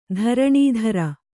♪ dharaṇī dhara